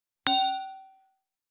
321ガラスのグラス,ウイスキー,コップ,食器,テーブル,チーン,カラン,